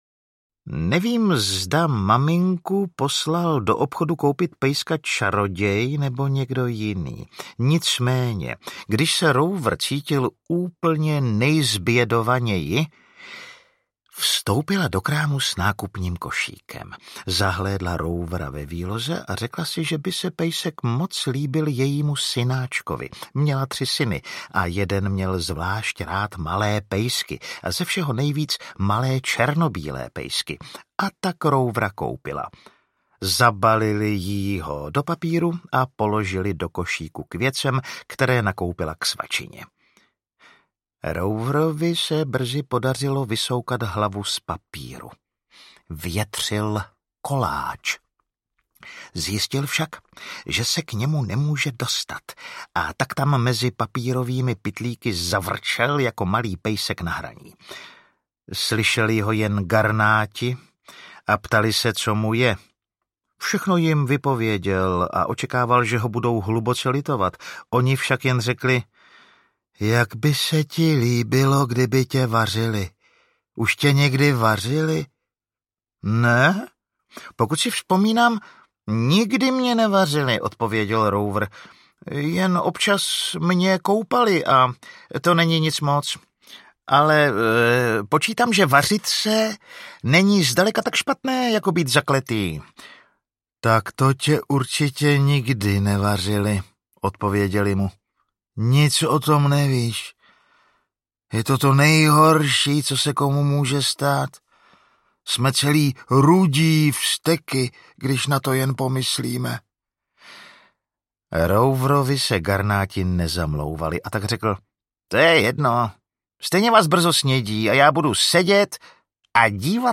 Příběhy z nebezpečné říše audiokniha
Ukázka z knihy